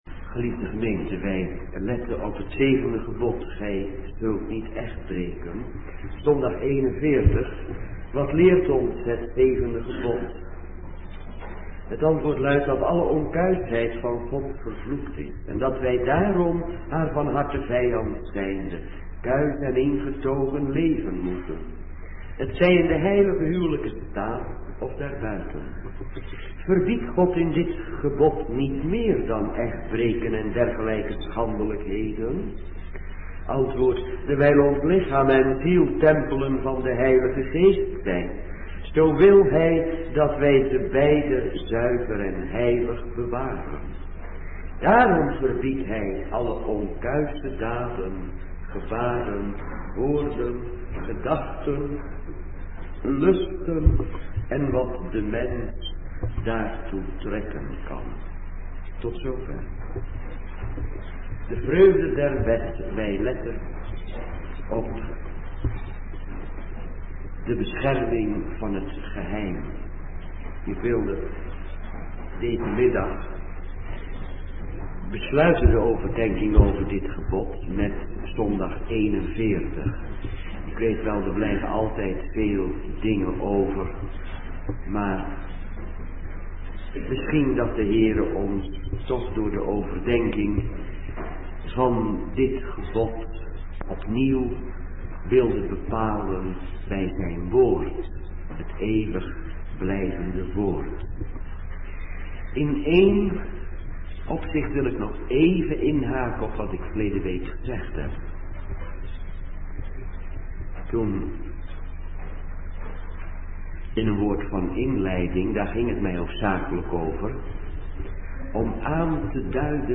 middagdienst -